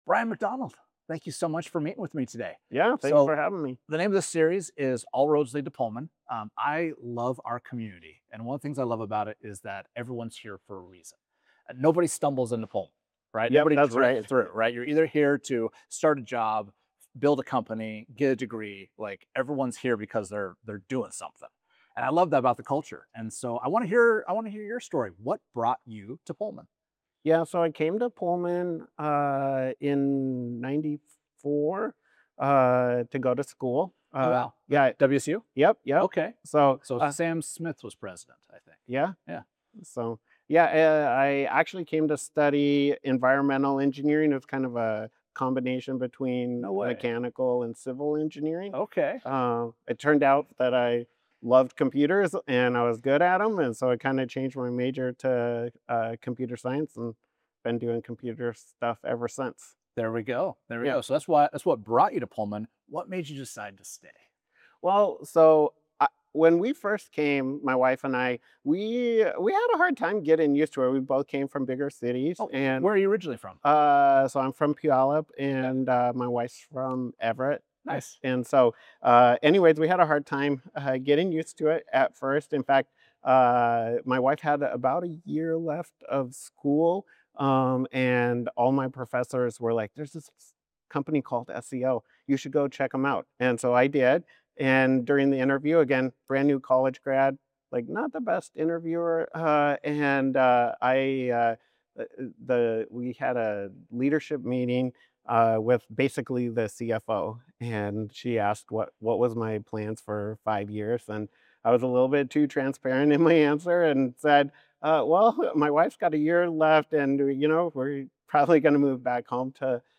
an insightful conversation about the role government is meant to have in the private sector and his belief that asking hard questions can be the solution to pressing city staff issues.